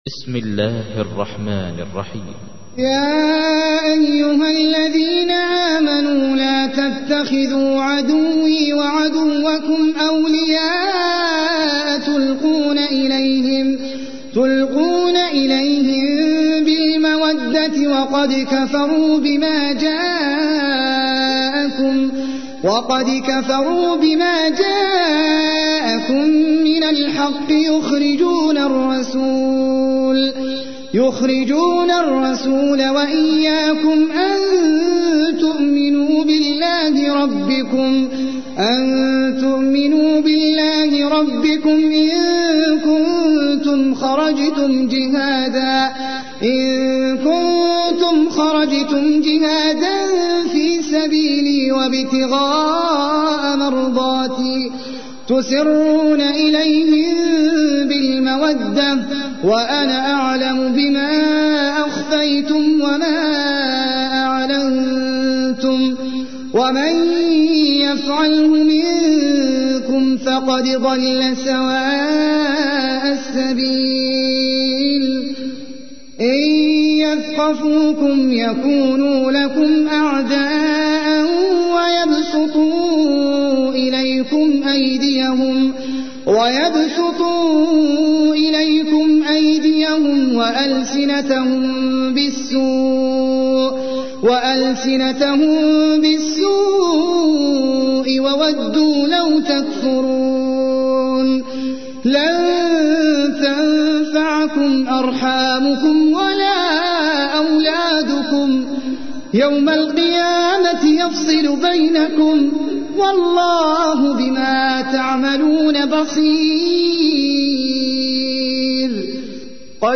تحميل : 60. سورة الممتحنة / القارئ احمد العجمي / القرآن الكريم / موقع يا حسين